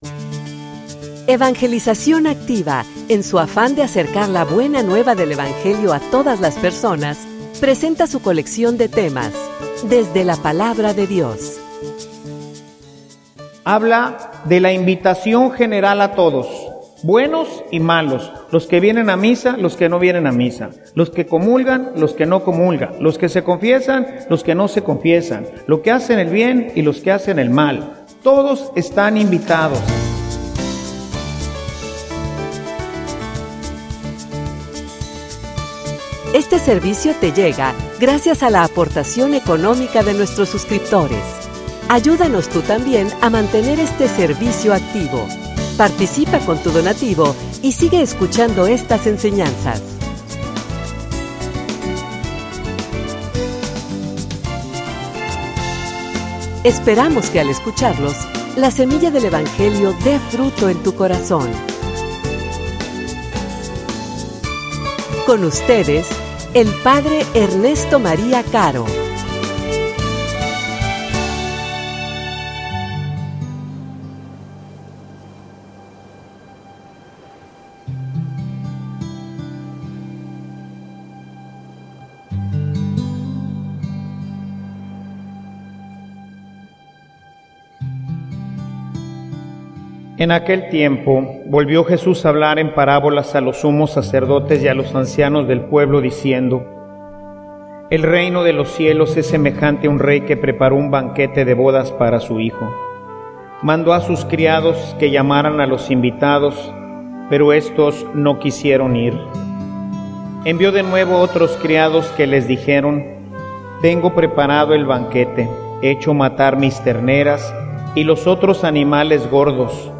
homilia_No_te_vayas_a_quedar_en_silencio.mp3